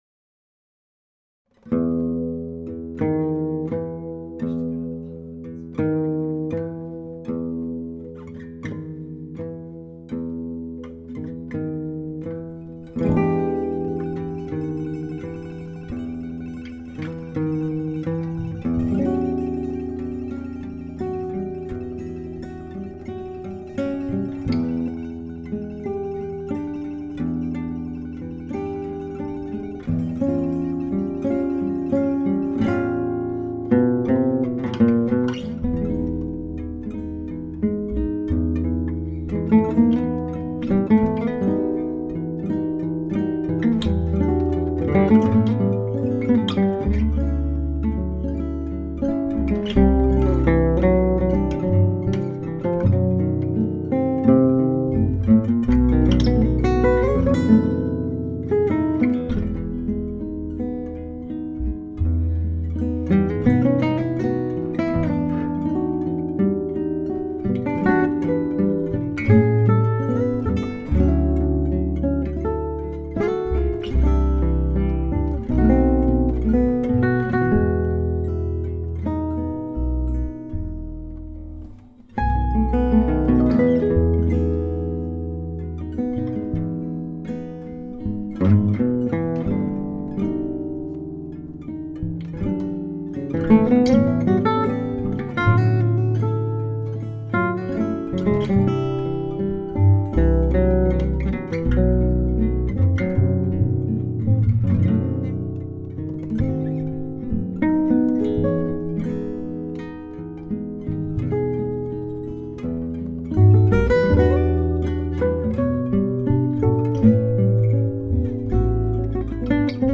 I've just recorded this slow version of Troublant Bolero. The guitar is my refinished Gitane 255 which is well suited to this sort of playing. Bass is my EKO fretless acoustic.